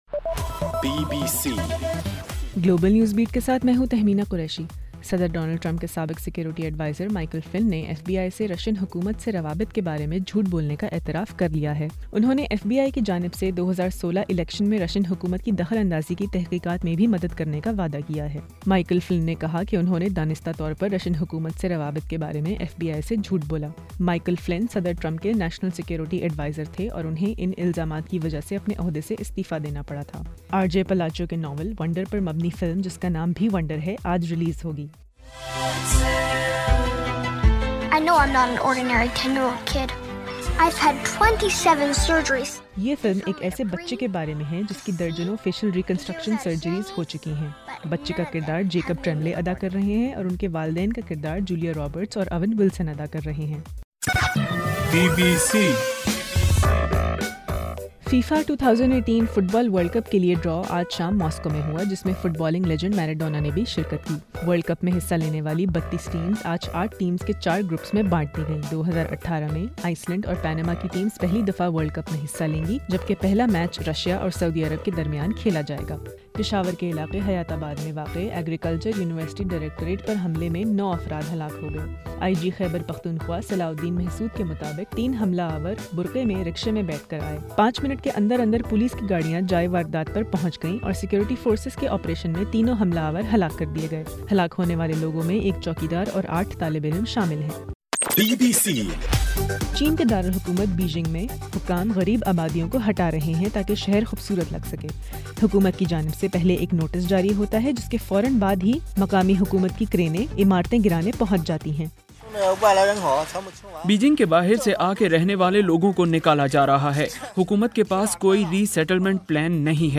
گلوبل نیوز بیٹ بُلیٹن اُردو زبان میں رات 8 بجے سے صبح 1 بجے تک ہر گھنٹےکے بعد اپنا اور آواز ایف ایم ریڈیو سٹیشن کے علاوہ ٹوئٹر، فیس بُک اور آڈیو بوم پر ضرور سنیے۔